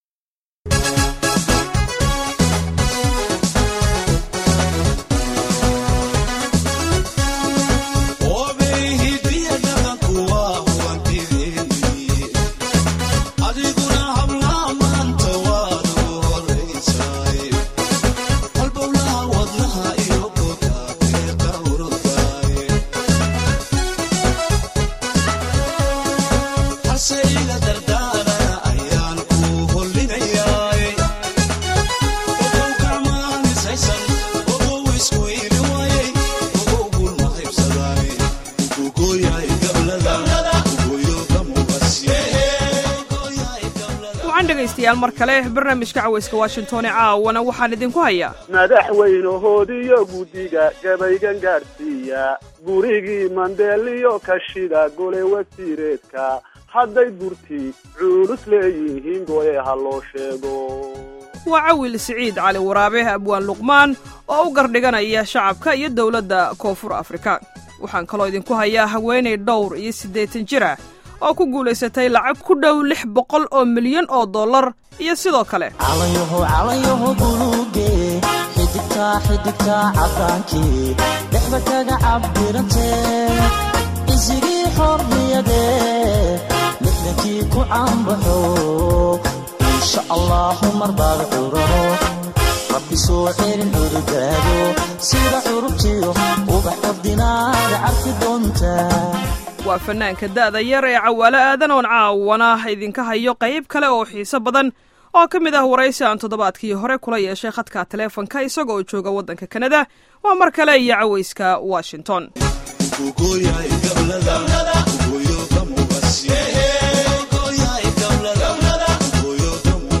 Barnaamijka Caweyska Washington waxaad maqli doontaan qeybtii 2aad ee wareysigii fanaanka Cawaale Aadan, sidoo kale barnaamijka waxaad ku maqli doontaan Haweeney sideetan kor u dhaaftay oo maaliyiin doolar ku guulaysatay.